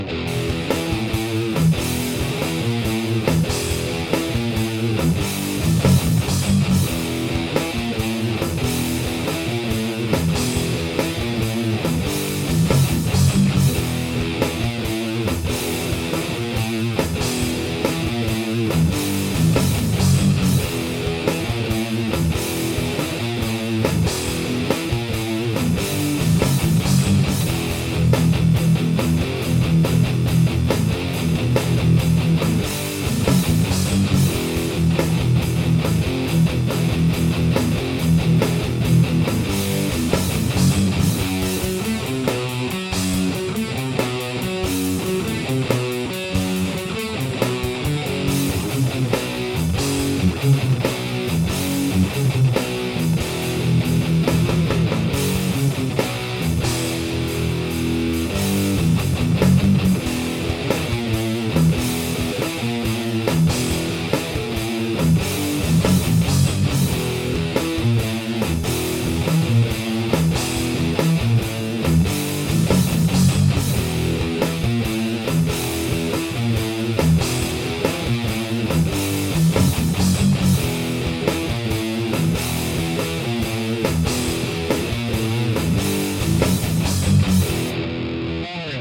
avec différents simulateurs d'ampli logiciels
en canal saturé
Guitare : Ibanez mc300
Je branche ma guitare sur la prise micro/line du rack.